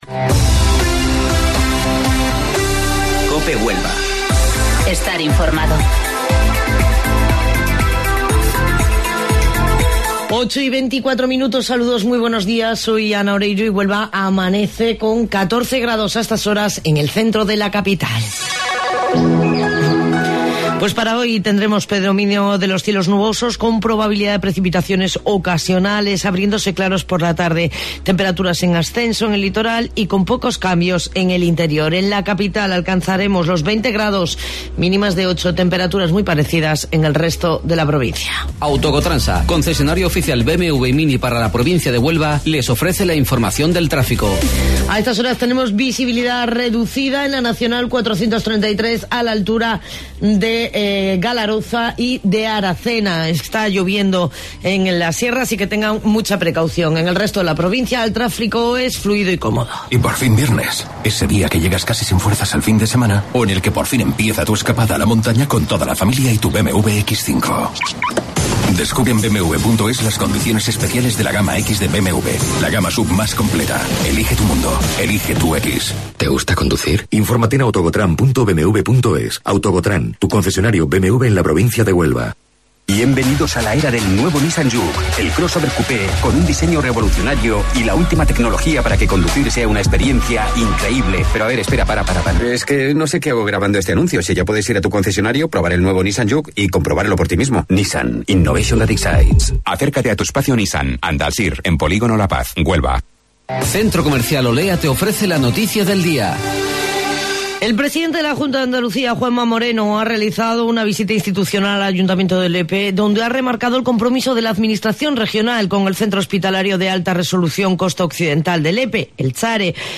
AUDIO: Informativo Local 08:25 del 17 Enero